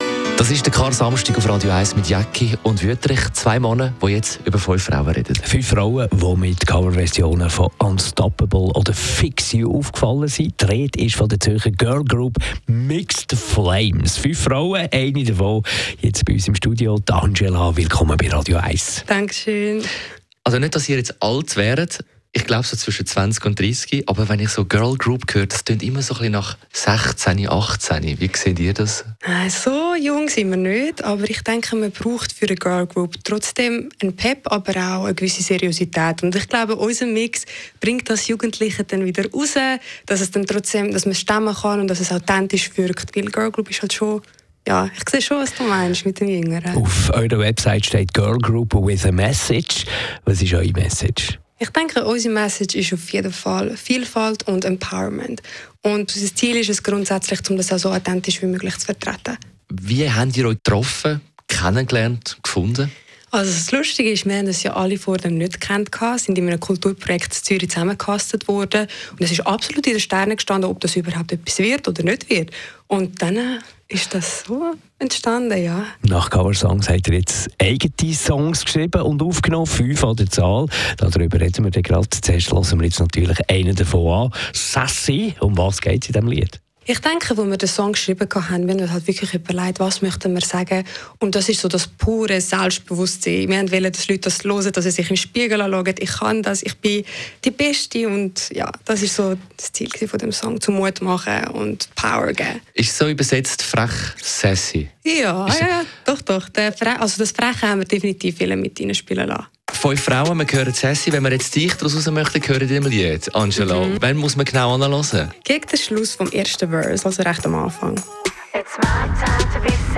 Presse, Medien Mixed Flames, Beiträge zu Mixed Flames, TV Mixed Flames, Interview Mixed Flames